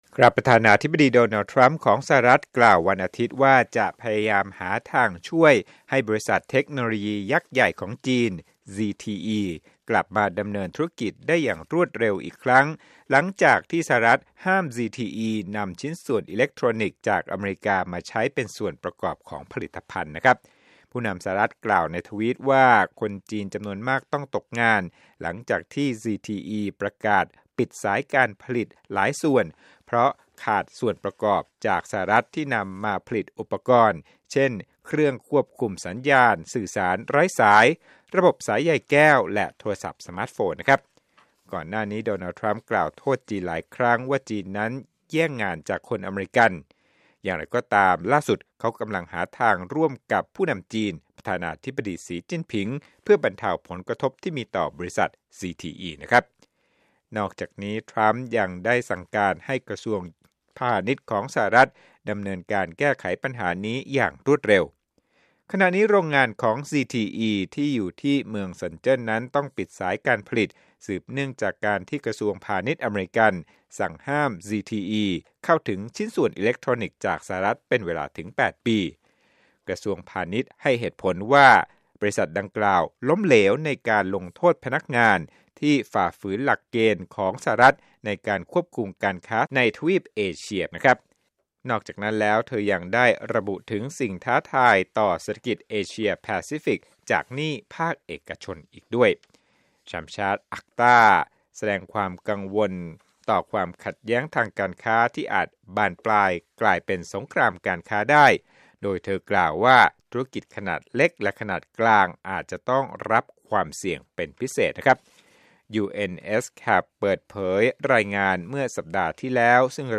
รวมข่าวธุรกิจ 5/13/2018